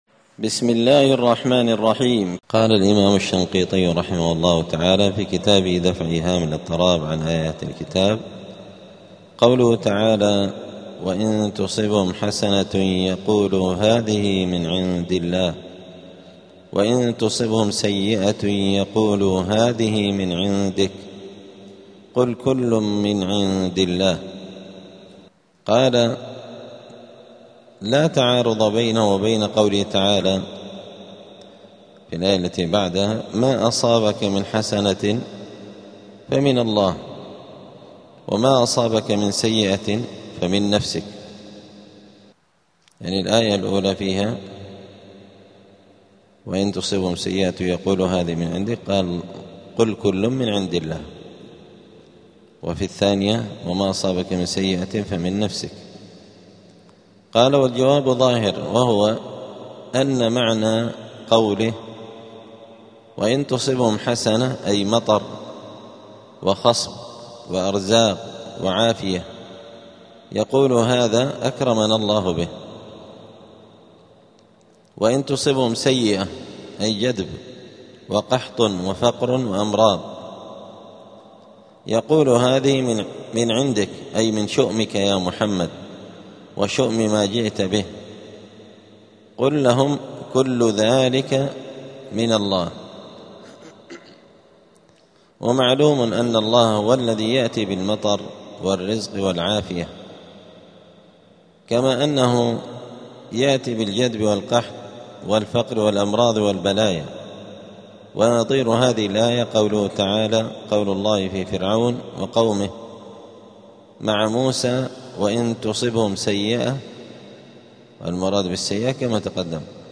*الدرس الرابع العشرون (24) {سورة النساء}.*